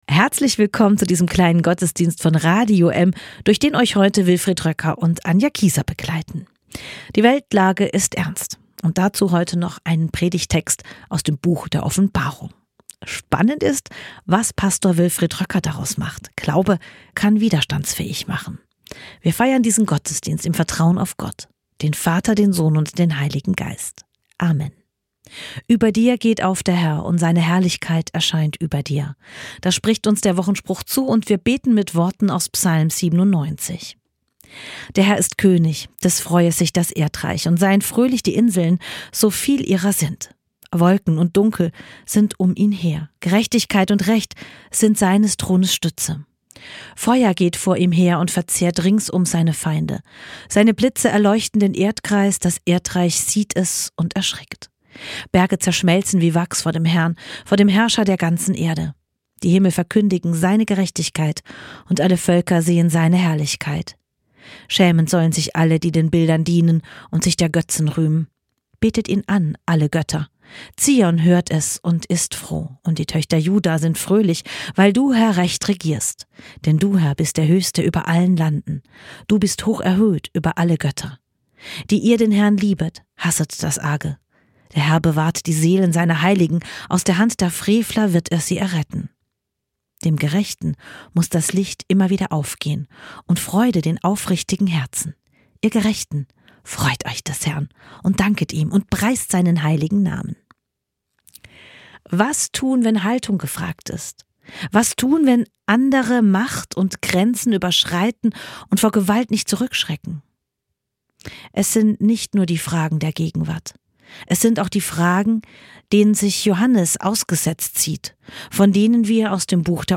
Weltkrisen, Machtspiele, Angst – und doch Hoffnung: Eine persönliche Predigt über Johannes, Bonhoeffer und die Kraft einer Theologie des Widerstands, die Mut macht und Zukunft eröffnet.